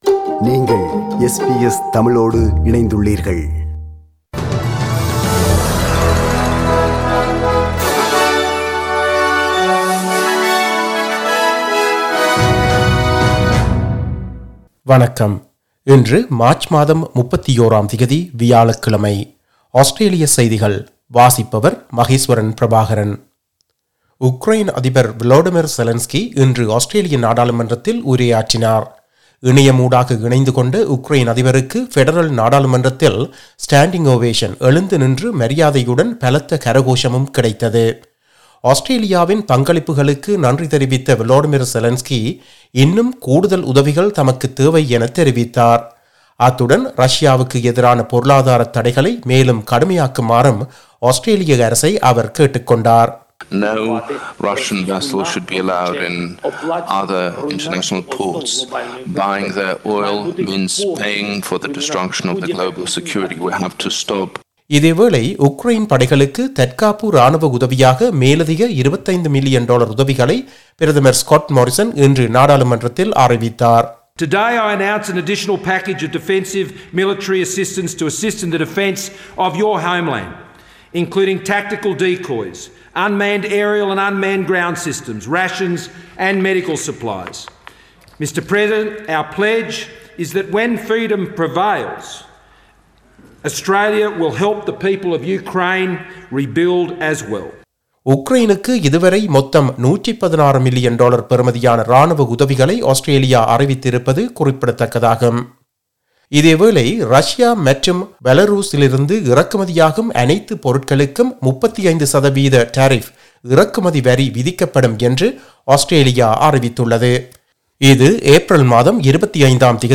Australian news bulletin for Thursday 31 March 2022.